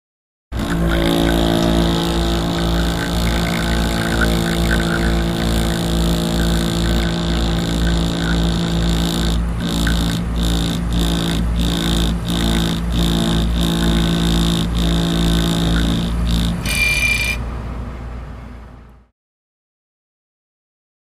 Test Tube Mixer | Sneak On The Lot
Test Tube Shaker; Motor / Fan Noise In Background, Low-mid Frequency Vibration In Foreground ( Sounds Like A Didgeridoo ), Water, Buzzer; Close Perspective. Hospital, Lab.